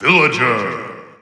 The announcer saying Villager's name in English releases of Super Smash Bros. 4 and Super Smash Bros. Ultimate.
Villager_English_Announcer_SSB4-SSBU.wav